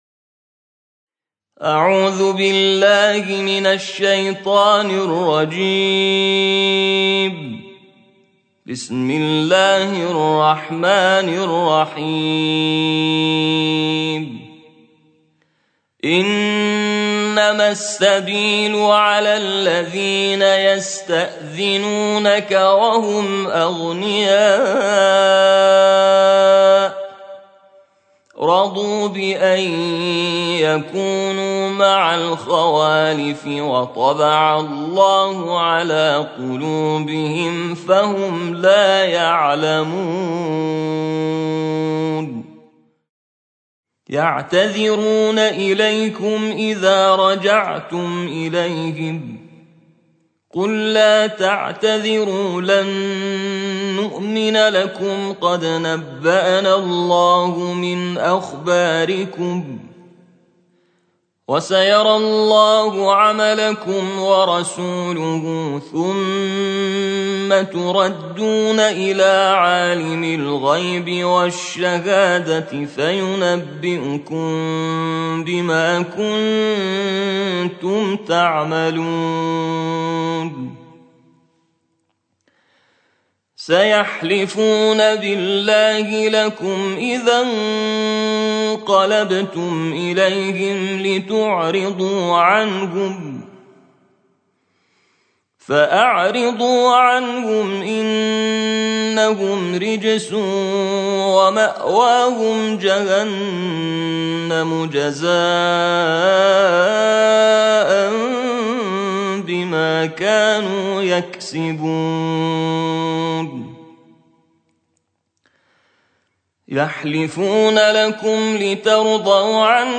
ترتیل جزء یازدهم قرآن